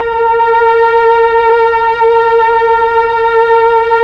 Index of /90_sSampleCDs/Keyboards of The 60's and 70's - CD1/STR_Melo.Violins/STR_Tron Violins
STR_TrnVlnA#4.wav